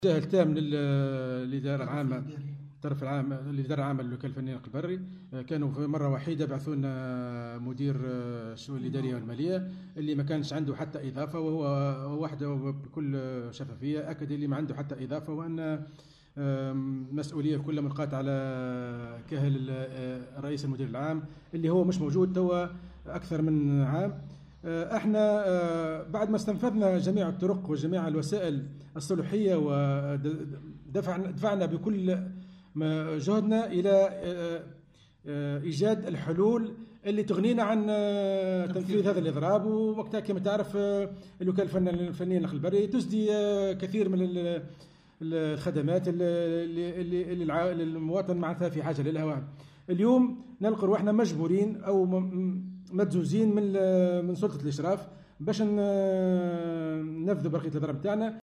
في تصريح لمراسلة "الجوهرة أف أم"